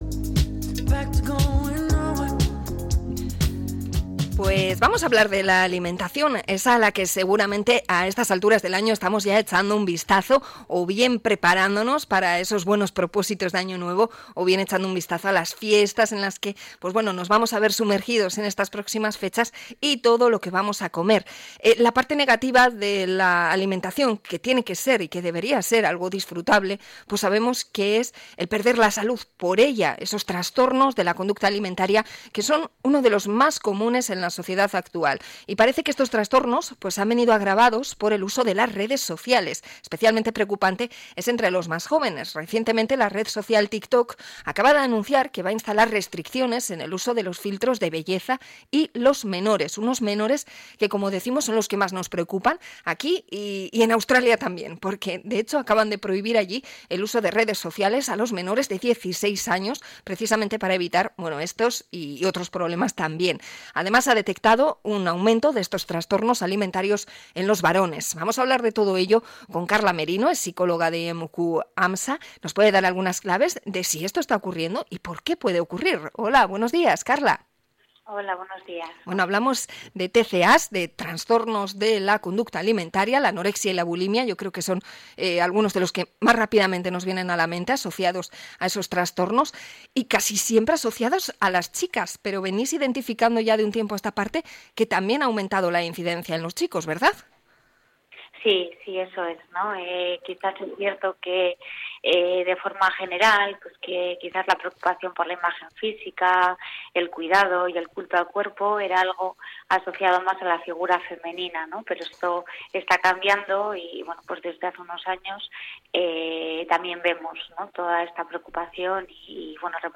Entrevista a psicóloga sobre trastornos alimentación chicos